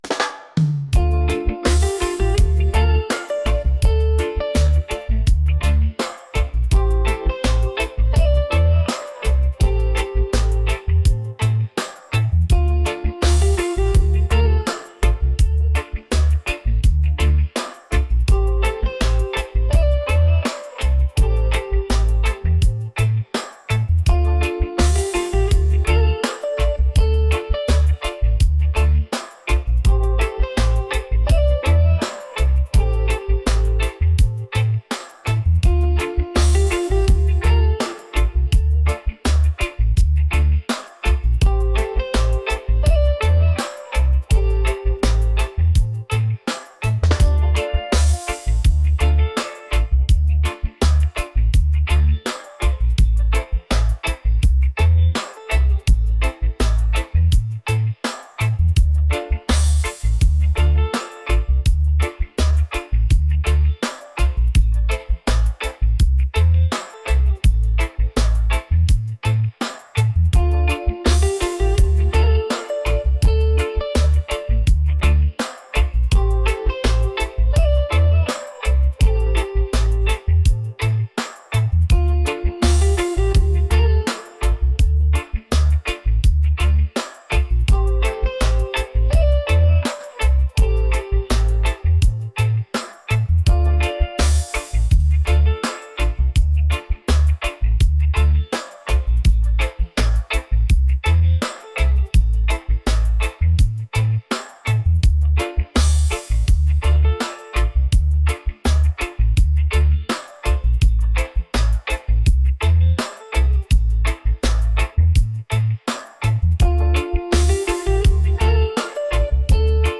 reggae | romantic | smooth